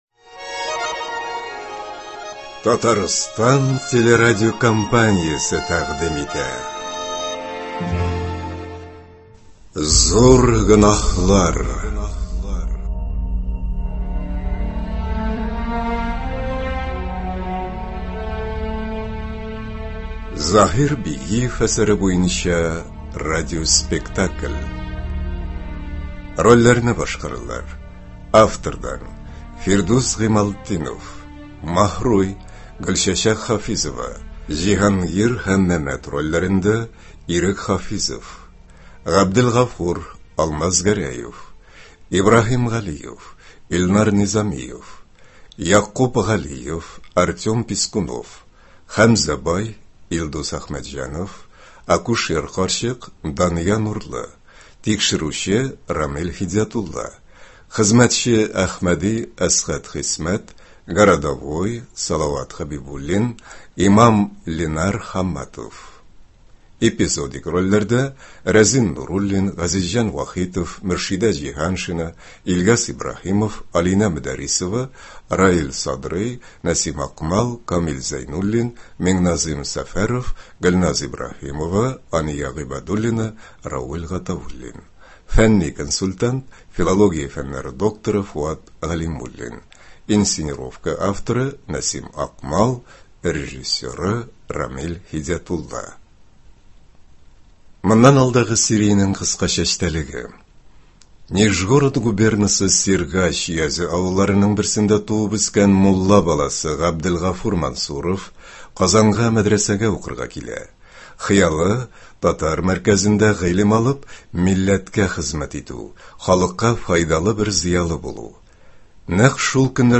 Татар әдәбияты классигы Заһир Бигиевның “Зур гөнаһлар” әсәре буенча эшләнгән радиоспектакльнең премьерасы тыңлаучылар хөкеменә чыга.